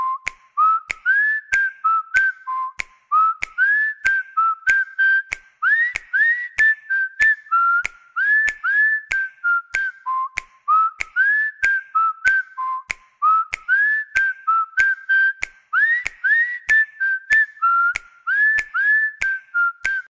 Kategori: Zil Sesleri